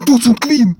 голосовые
электронные
Рингтон на эсперанто в жанре Speech.